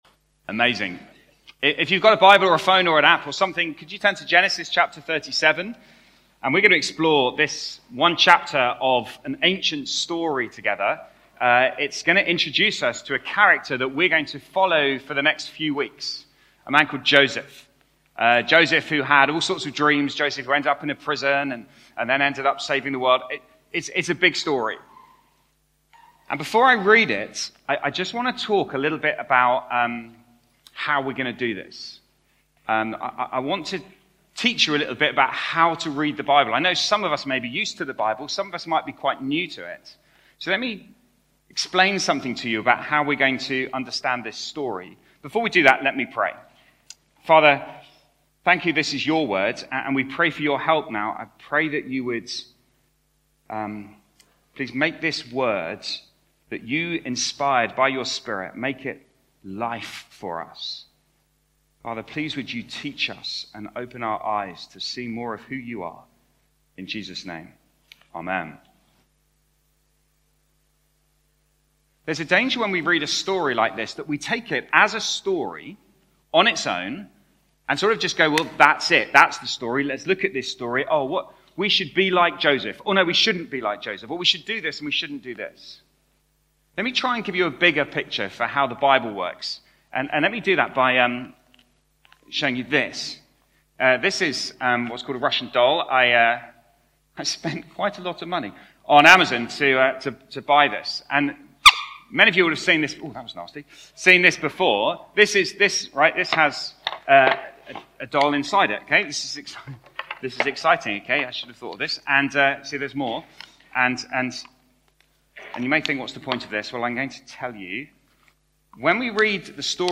Sermon-Sep-21.mp3